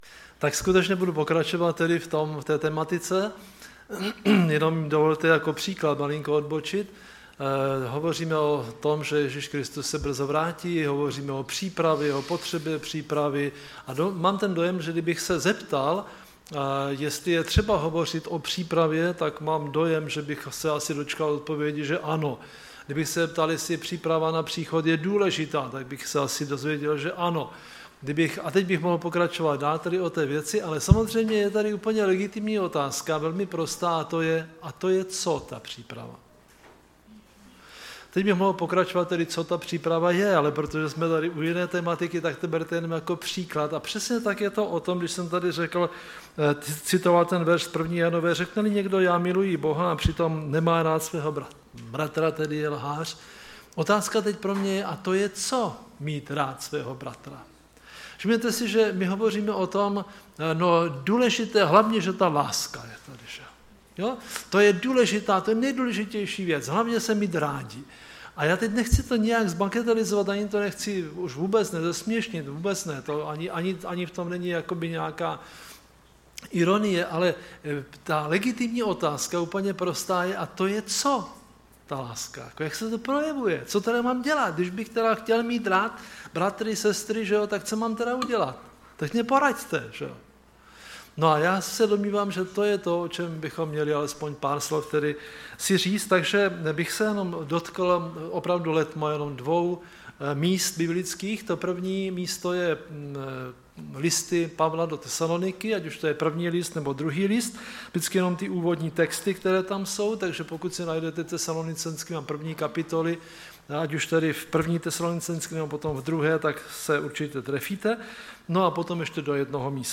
Typ Služby: Přednáška